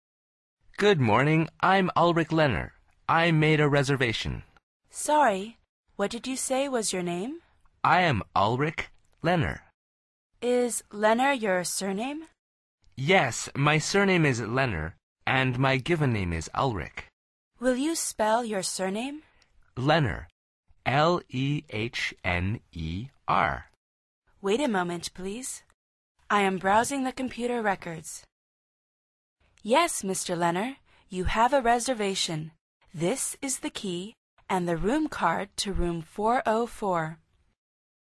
Pulsa la flecha de reproducción para escuchar el segundo diálogo de esta lección. Al final repite el diálogo en voz alta tratando de imitar la entonación de los locutores.